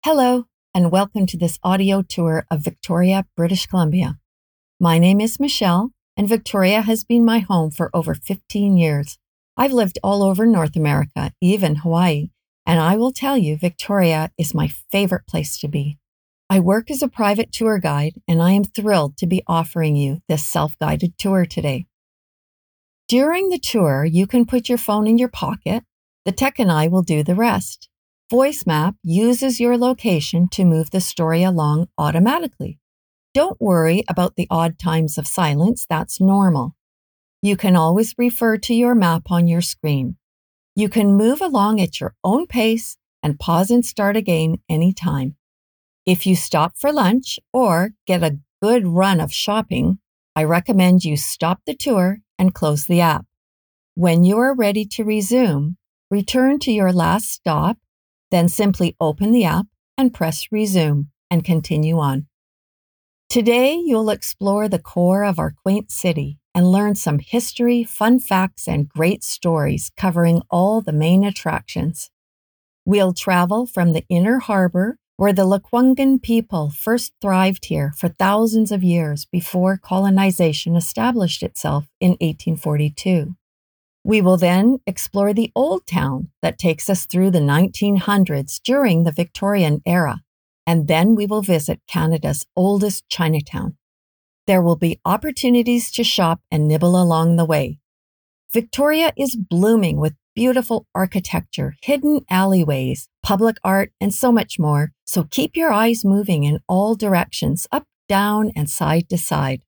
Audio Tour
Narrated by Passionate, local expert